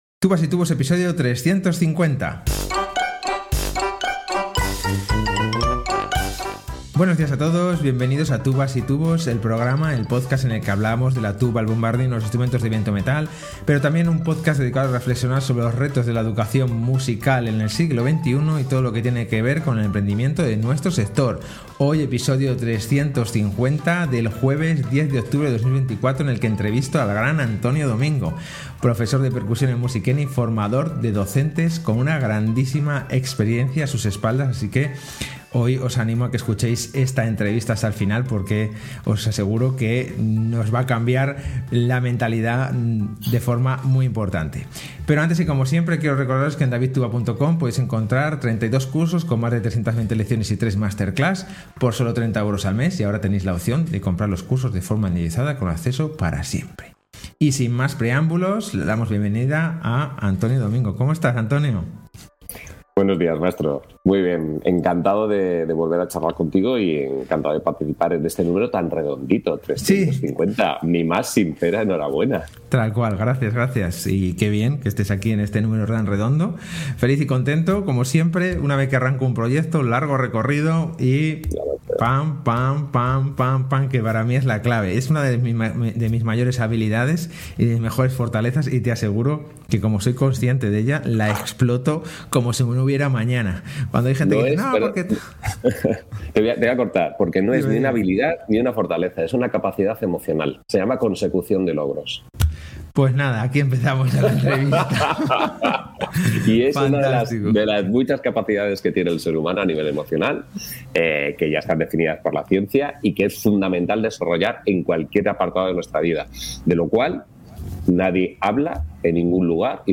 Hoy entrevista muy interesante sobre los pilares del buen docente